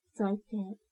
「怒」のタグ一覧
ボイス
女性